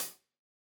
OpenHH Zion 2.wav